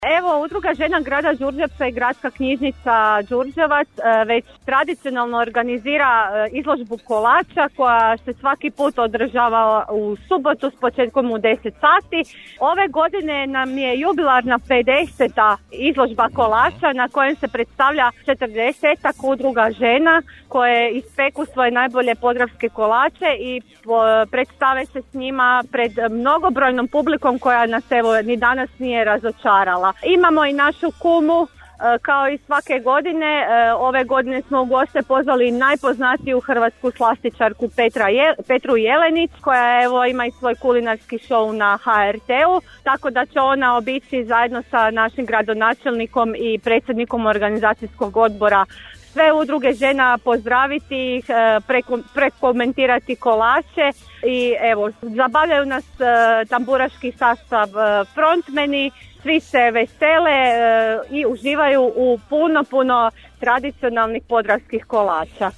S tržnice nam se javila ispred organizatora